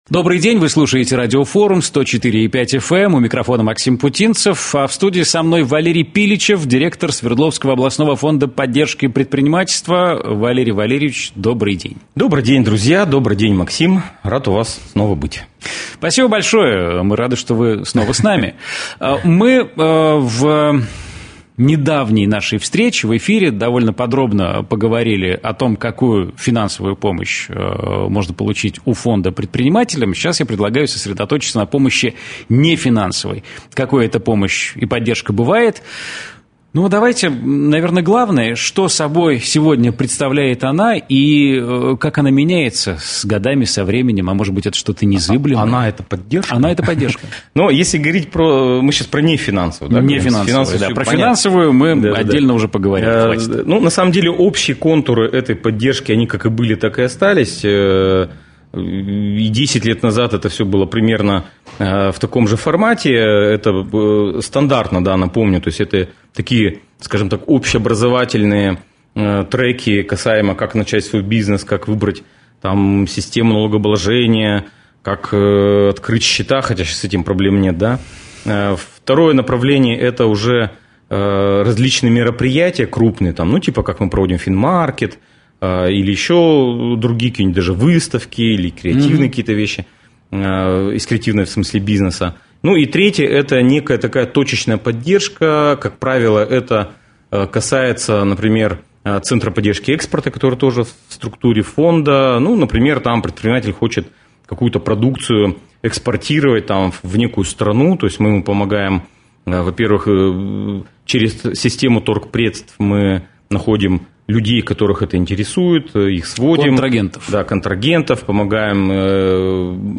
Интервью. СОФПП | Радио Форум